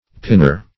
Pinner \Pin"ner\, n.